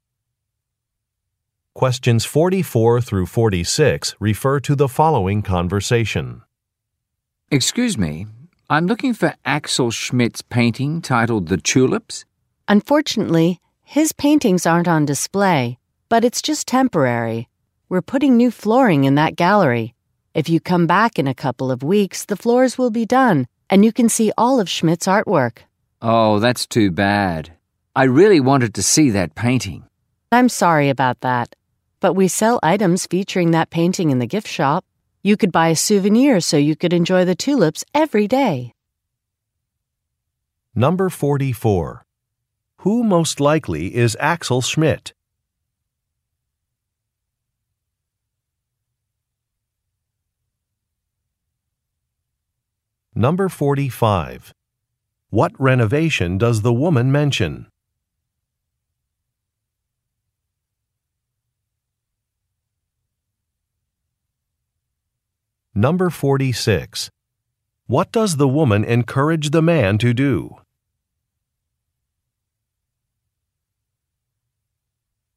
Question 44 - 46 refer to following conversation: